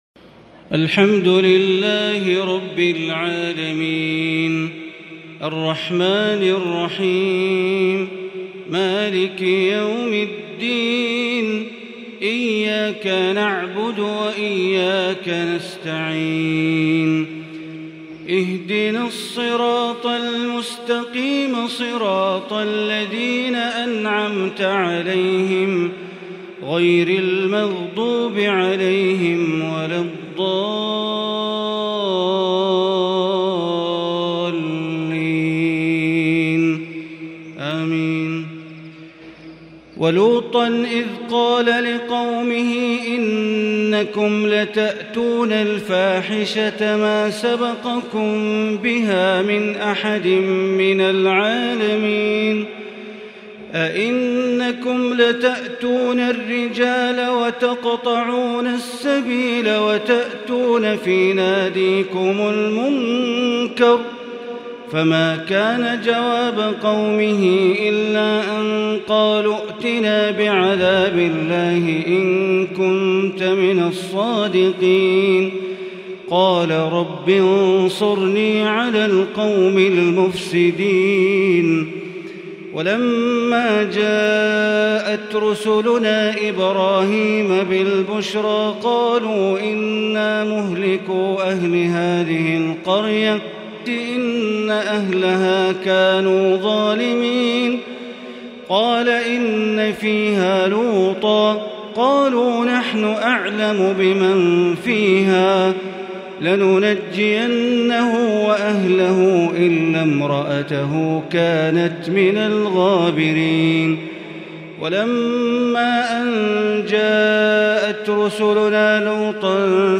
تلاوة رائعة وابداع مُتجدد من الشيخ بندر بليلة سورتي العنكبوت (28) إلى الروم (45) | ليلة 24 رمضان 1442هـ > تراويح ١٤٤٢ > التراويح - تلاوات بندر بليلة